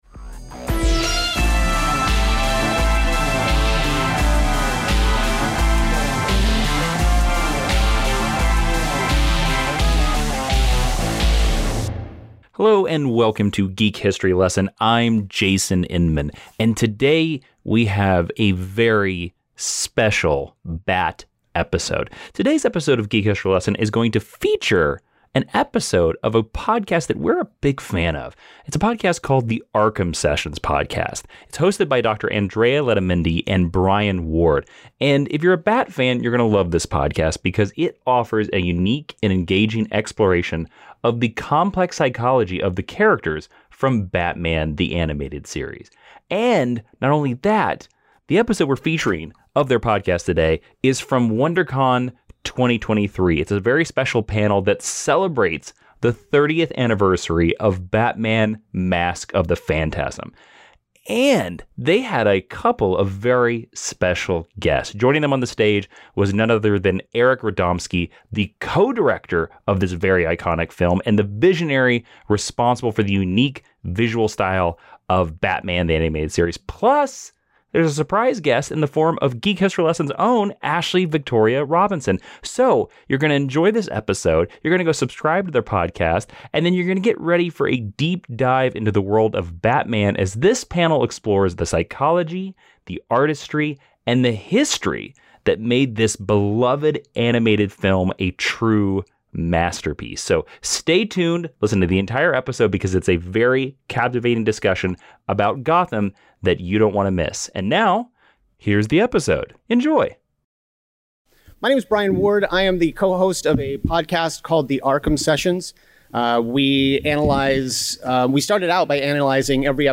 Celebrate the 30th anniversary of Batman: Mask of the Phantasm with a very special episode recorded live at Wondercon 2023.